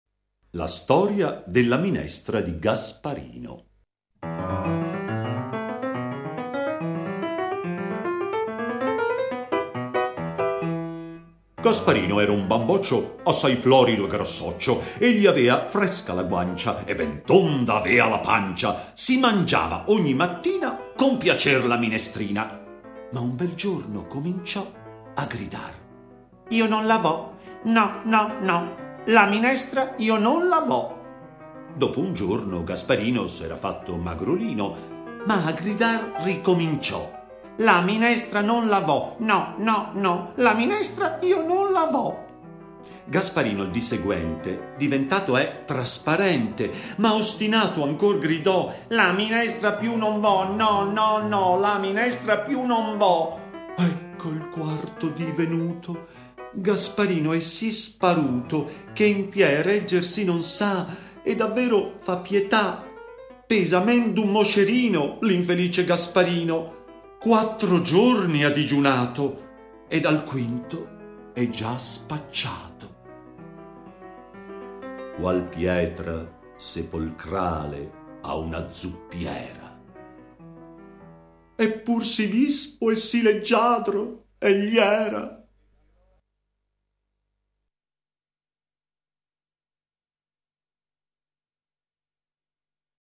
Divertenti poesie con sottofondo musicale eseguito da alcuni allievi